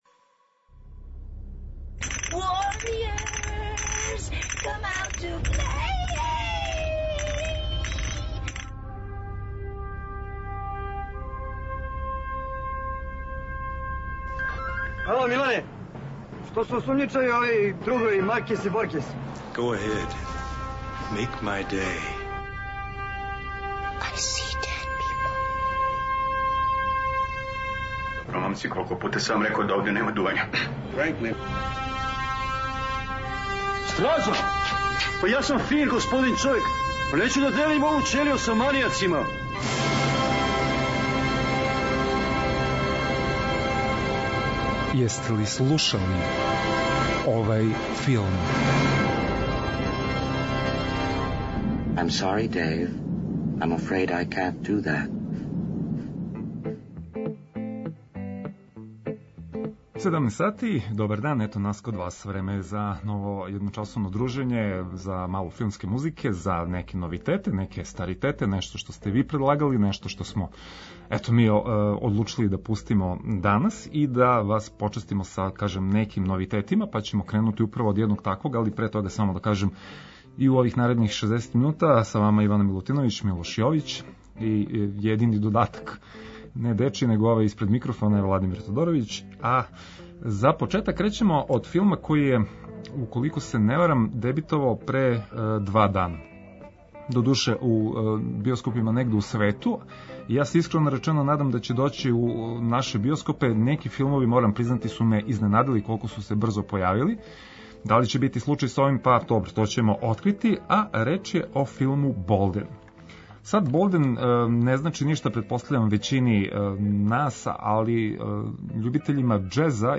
Филмска музика и филмске вести. Заједно ћемо се присетити мелодија које ће нам вратити у сећање сцене из филмова, али и открити шта нам то ново спремају синеасти и композитори.